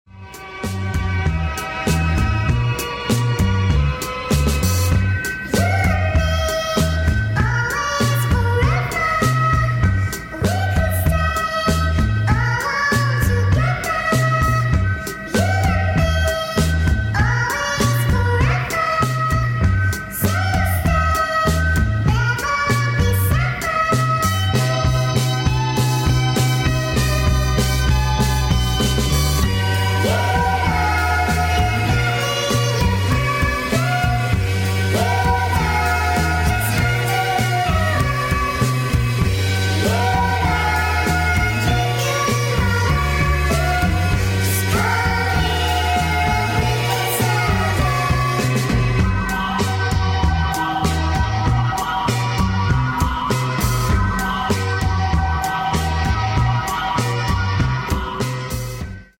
sped up!